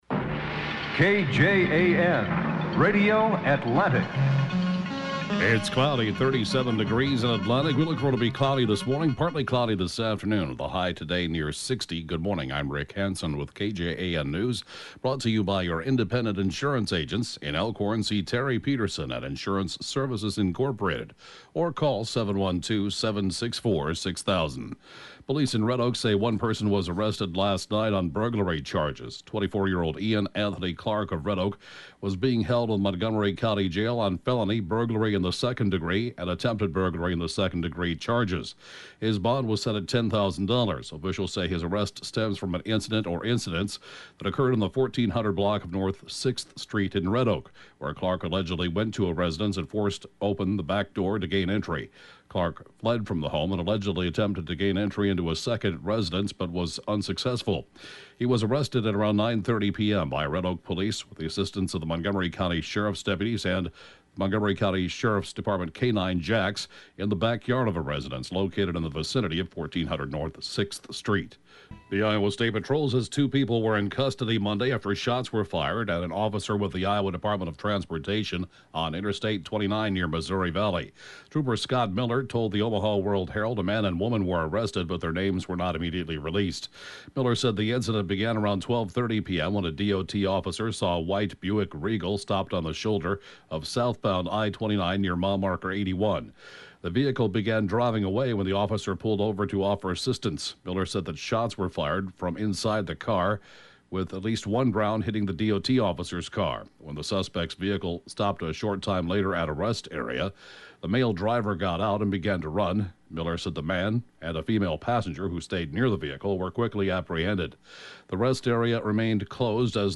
KJAN News can be heard at five minutes after every hour right after Fox News 24 hours a day!